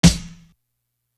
Sun Snare.wav